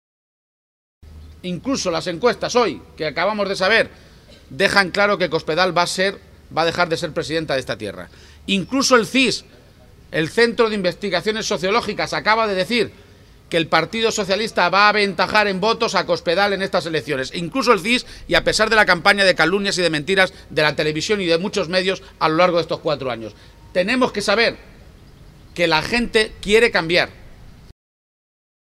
García-Page ha realizado esta valoración del macrosondeo del CIS, que sitúa al PSOE por delante del PP de Cospedal en estimación de voto, durante su intervención en el acto público que ha celebrado en la localidad ciudadrealeña de Almadén.